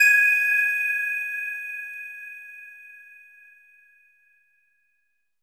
LEAD G#5.wav